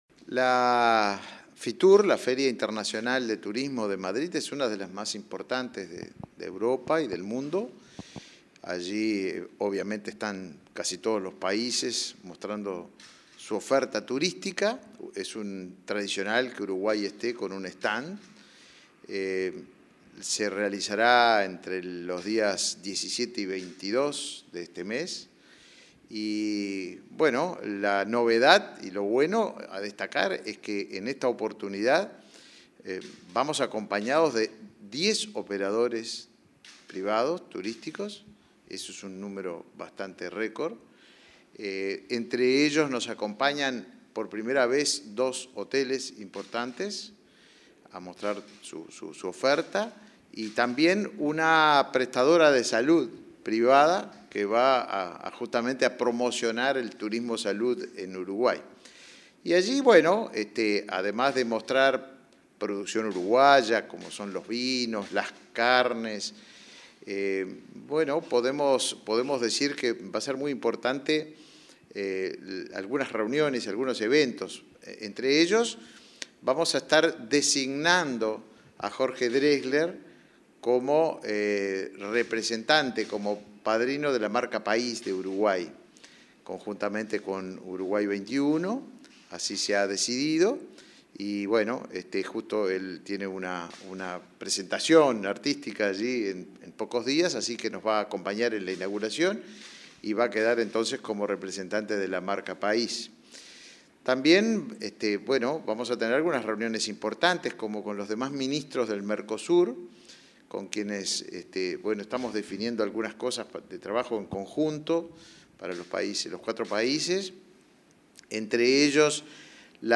Entrevista al ministro de Turismo, Tabaré Viera